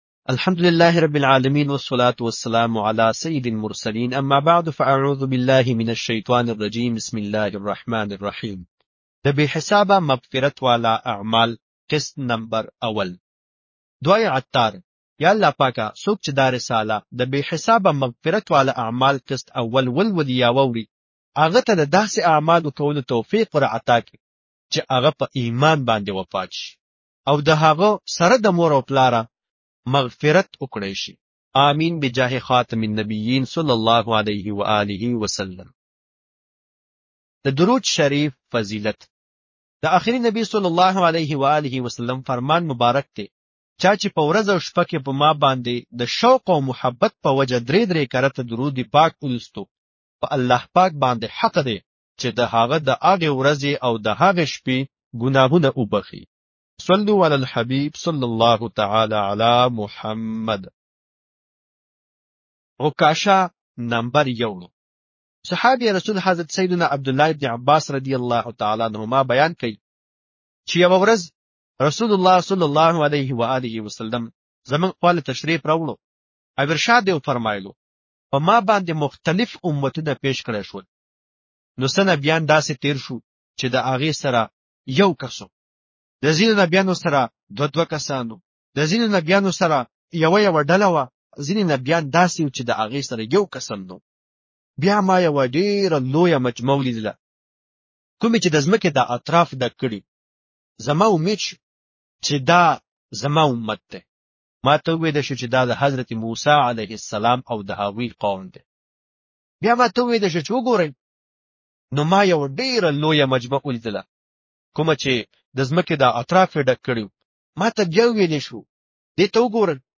Audiobook – Behisab Maghfirat Ke Aamal (Pashto)